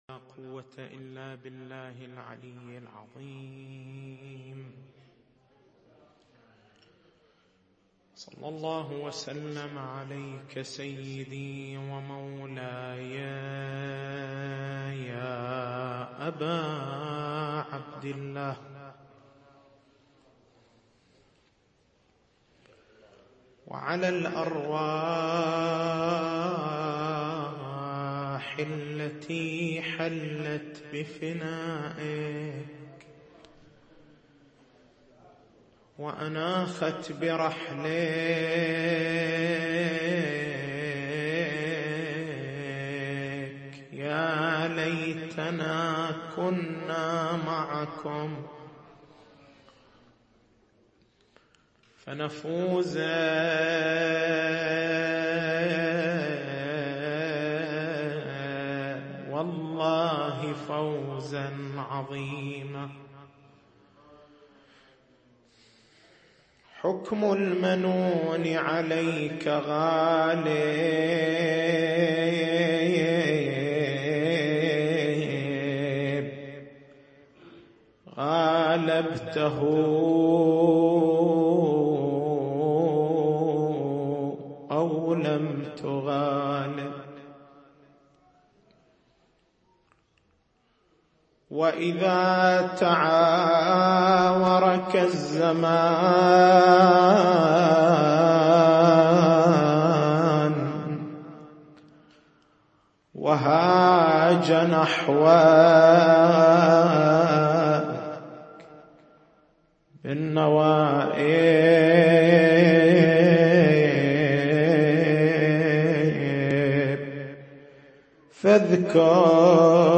تاريخ المحاضرة: 19/01/1434 نقاط البحث: هل يُكْرَه لبس السواد في الشريعة المقدّسة؟ ما الدليل على استحباب لبس السواد في عزاء سيّد الشهداء (ع)؟